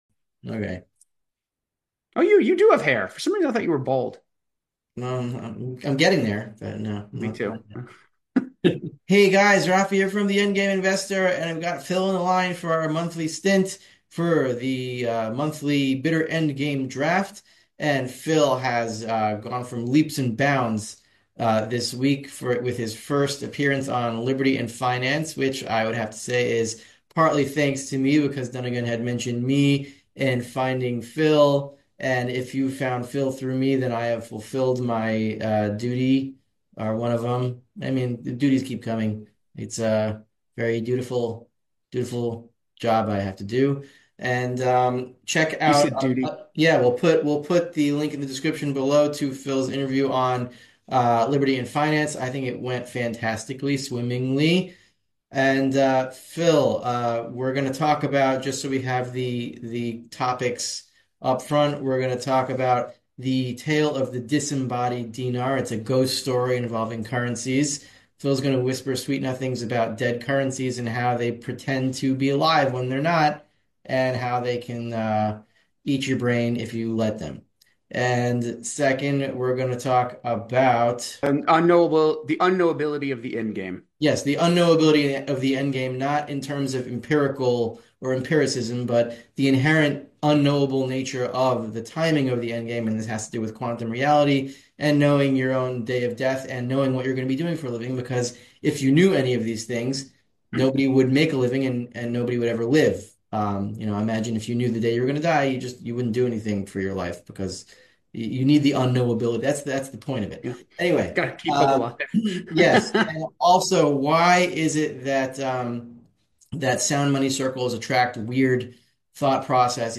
monthly chat. They explore the mysterious tale of the disembodied dinar, a ghost story about currencies that seem alive but aren't.